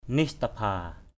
/nɪ’s-ta-bʱa:/ (đg.) chết = mourir.